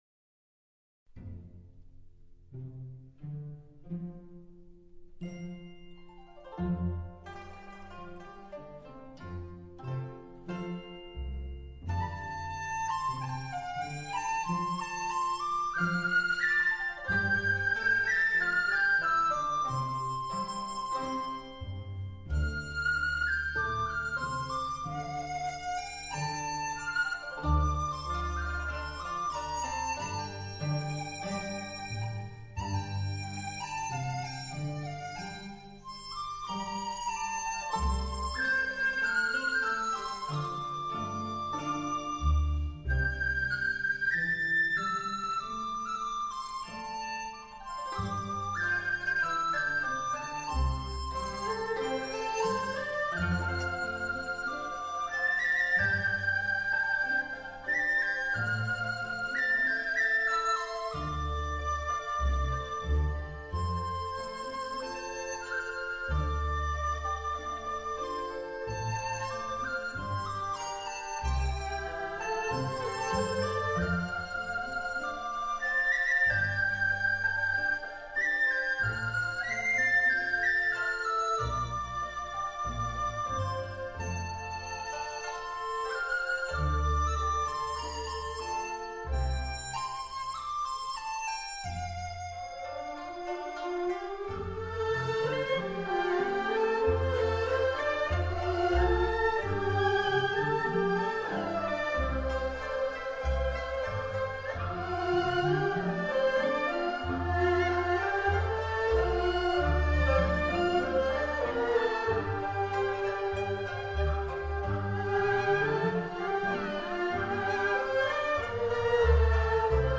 Música Tradicional China, Música del Dragón 中国传统音乐 音樂龍.mp3